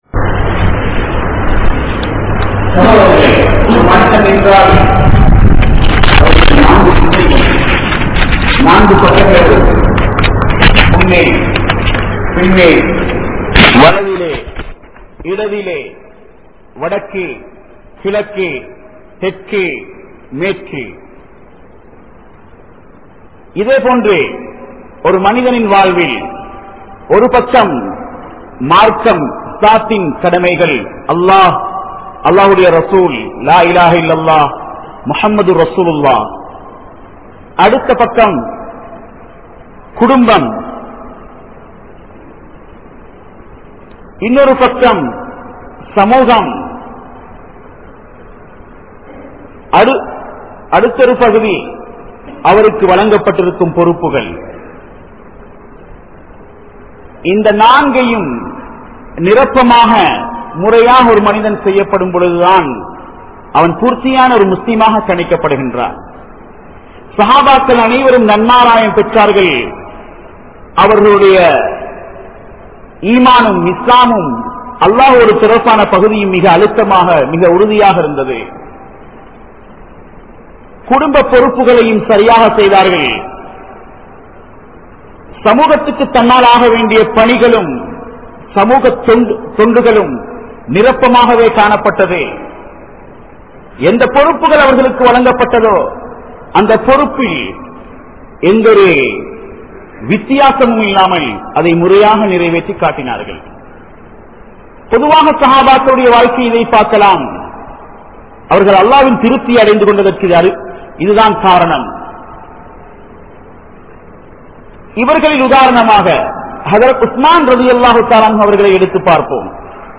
Muslim Samoohathai Pirithu Vidatheerhal (Do not divide the Muslim community) | Audio Bayans | All Ceylon Muslim Youth Community | Addalaichenai